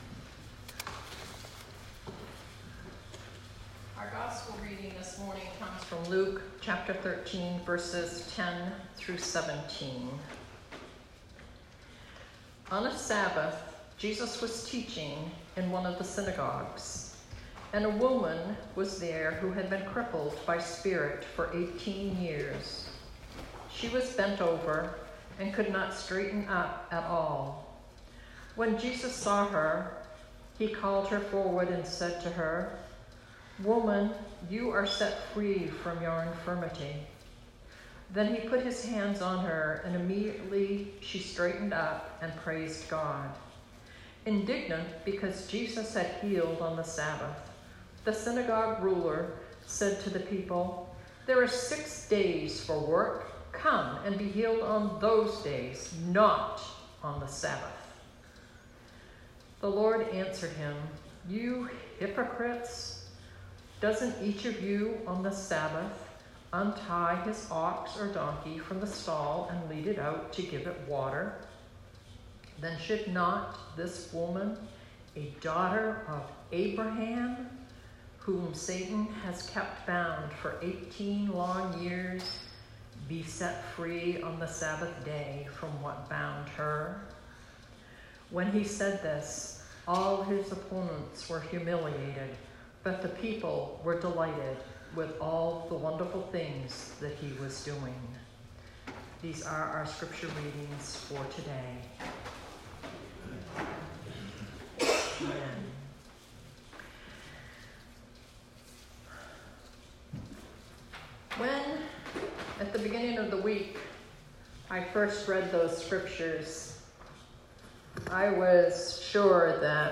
Sermon 2019-08-25